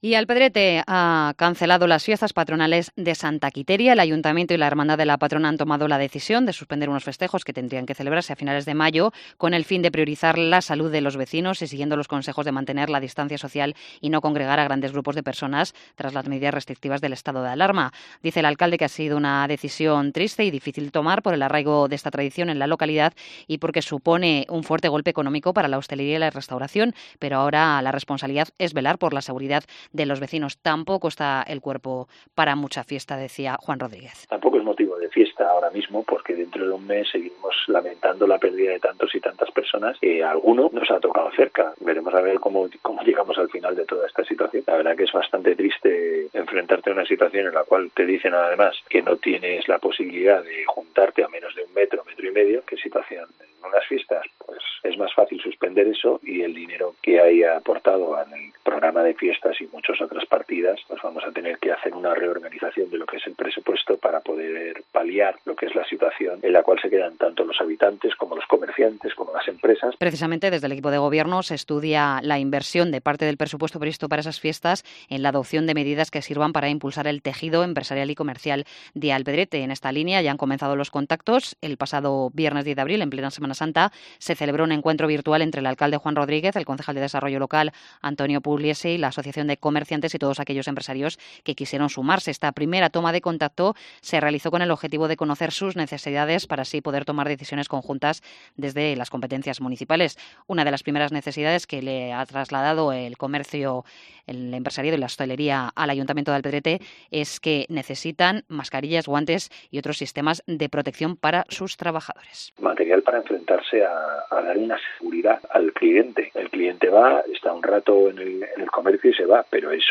Entrevista a Juan Rodríguez, Alcalde de Alpedrete en Cope de la Sierra